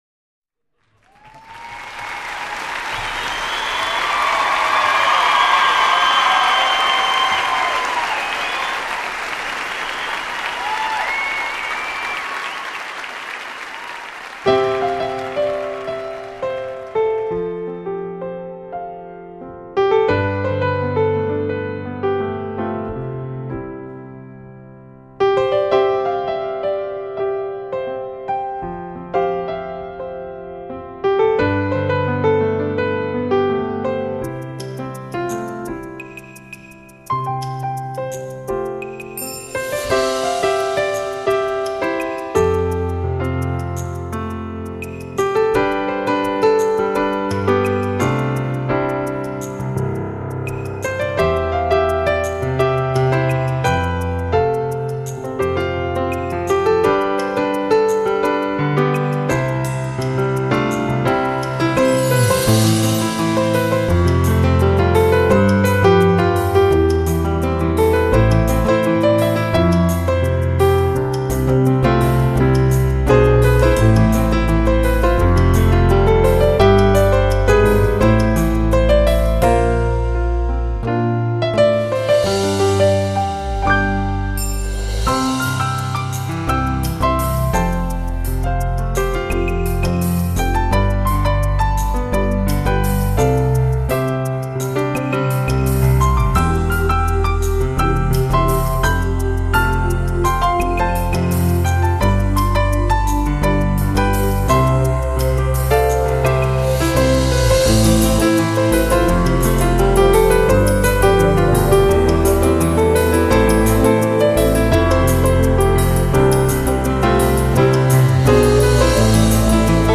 名家钢琴
2000年全美巡演鹽湖城實況錄音